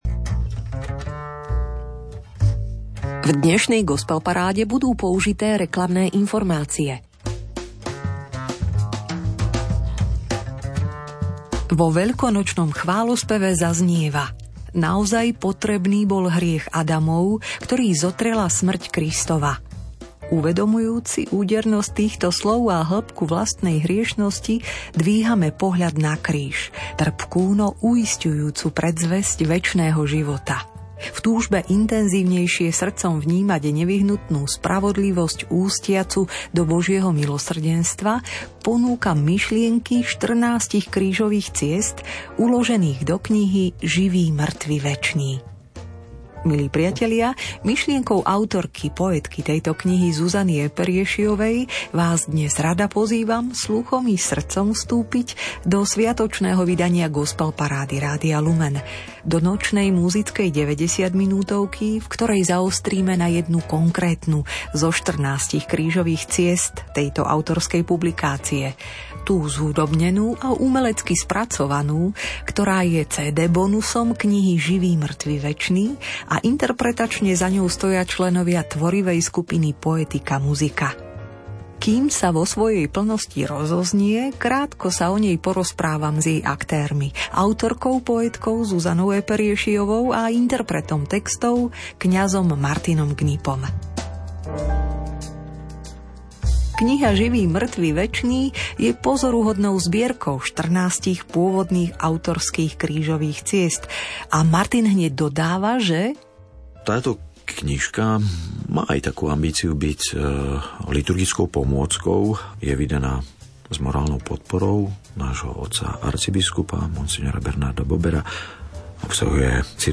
(repríza z 28.3.2024)